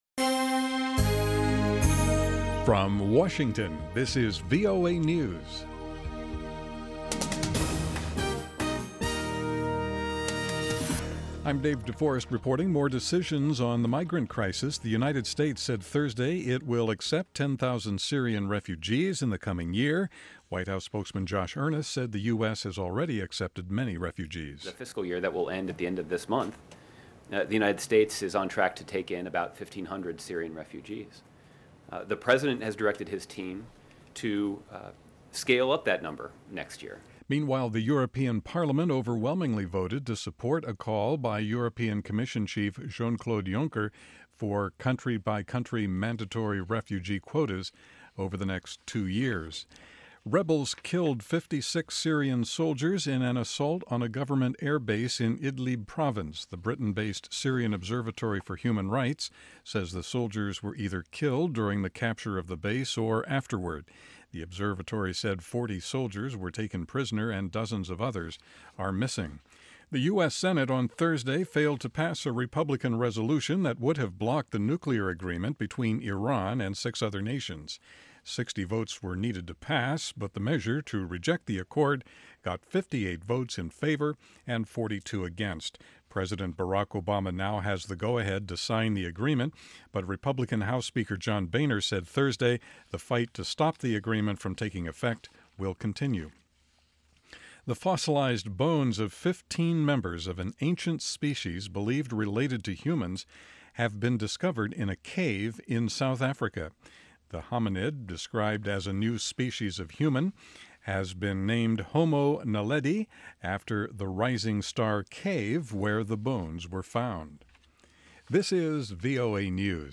اخبار